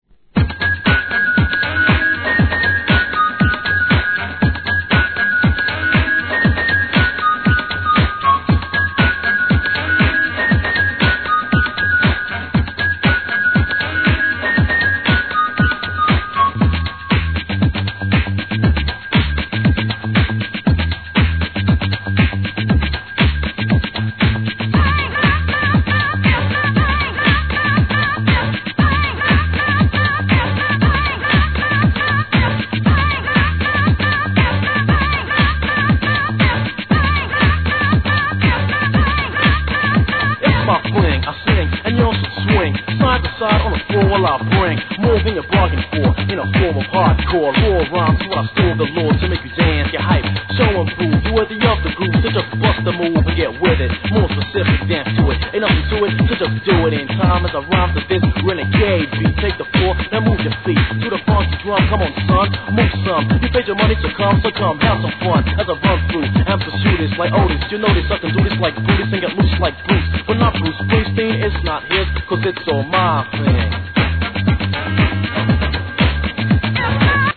12inch
HIP HOP/R&B